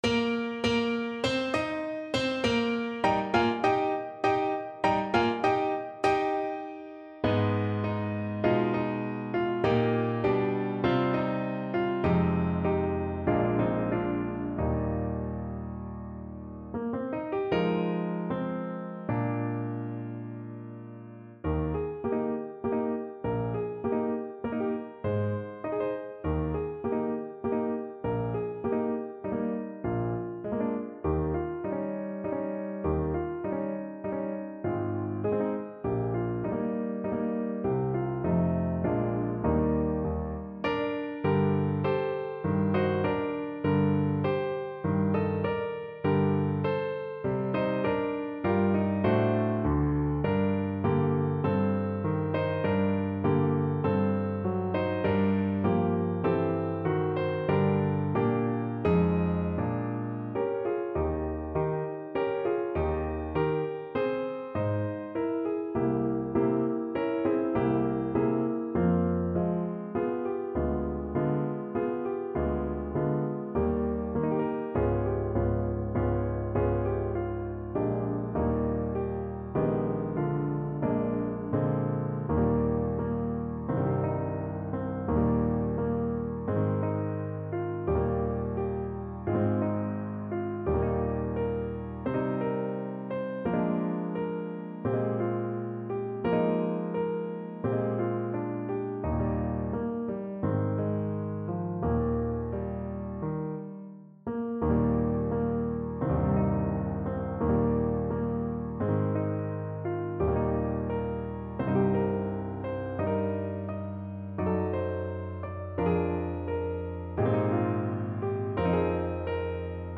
4/4 (View more 4/4 Music)
Moderato